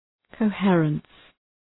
Προφορά
{kəʋ’hırəns}